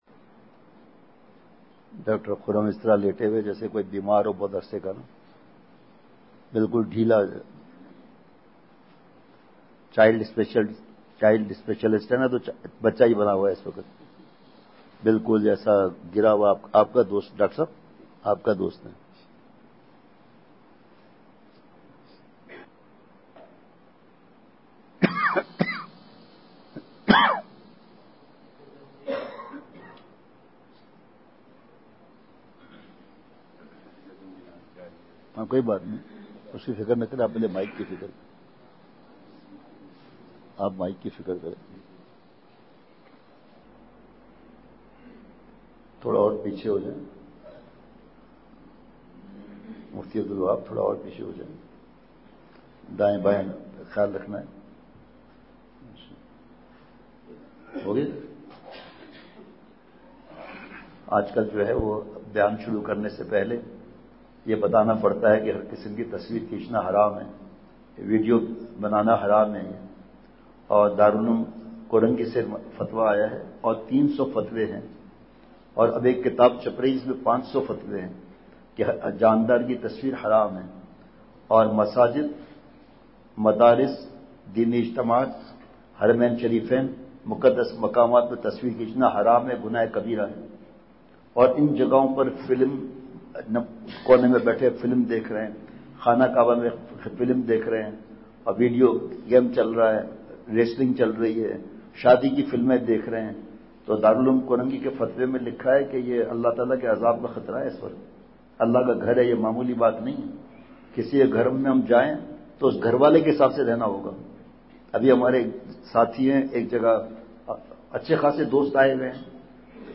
*بمقام:۔جامع مسجد سیدنا حسن چنار مارکیٹ پشاور،روالپنڈی*
*نمبر(8):بیان*